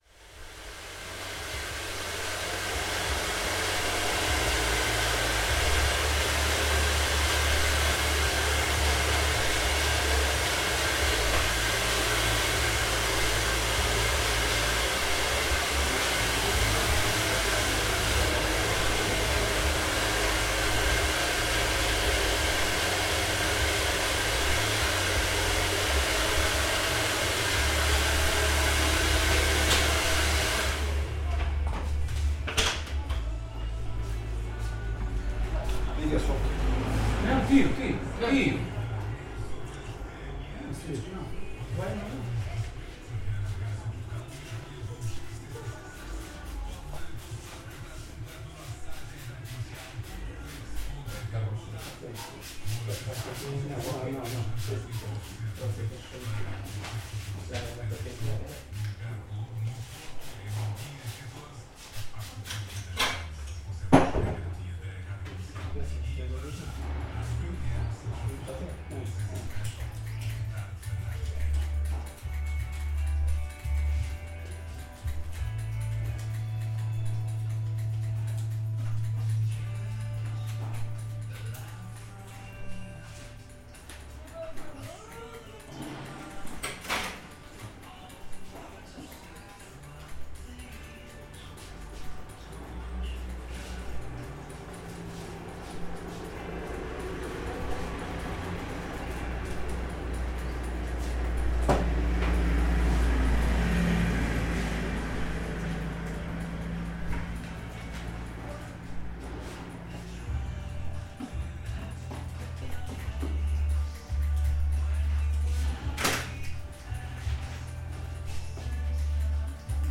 NODAR.00112 – Viseu: Rua do Adro- Barbearia em actividade
Gravação do som de uma barbearia tradicional da cidade: um misto de sons de secador de cabelo e de tesouras, com a rádio em pano de fundo. Gravado com Zoom H4.
Tipo de Prática: Paisagem Sonora Rural
Viseu-Rua-do-Adro-Barbearia-em-actividade.mp3